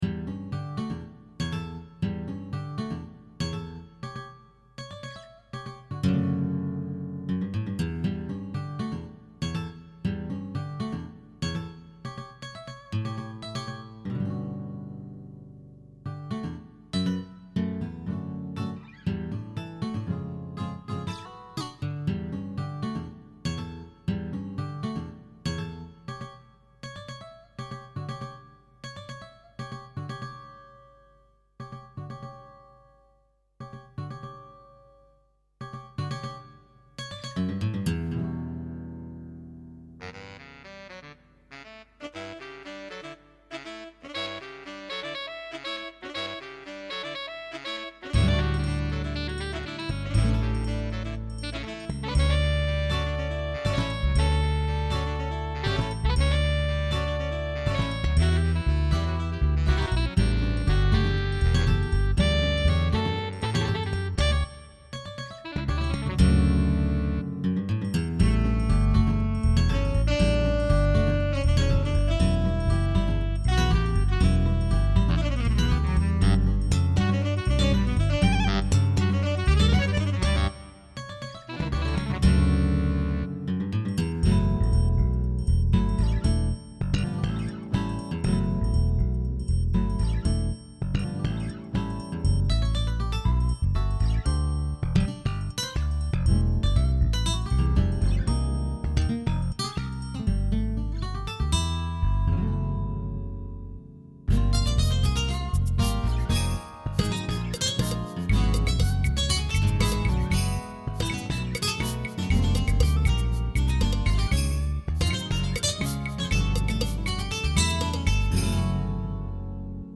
dance/electronic
NuJazz
Jazz